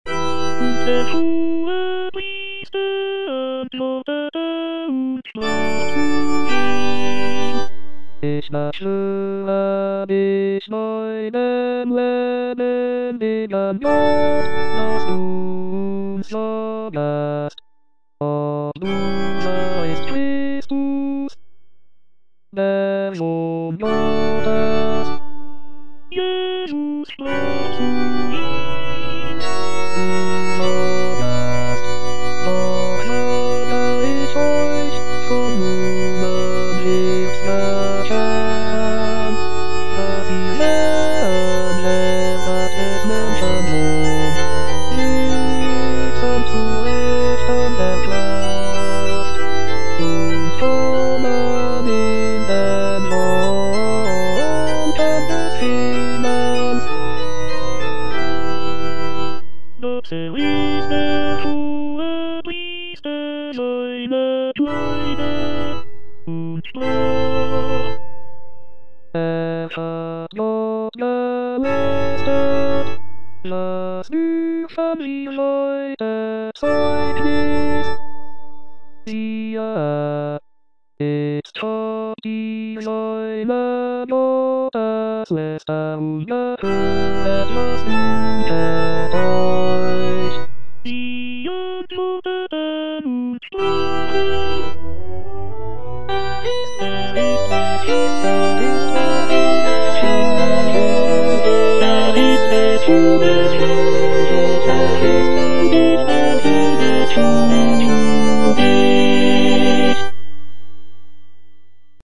J.S. BACH - ST. MATTHEW PASSION BWV244 42 - Und der Hohepriester antwortete (chorus II) - Tenor (Emphasised voice and other voices) Ads stop: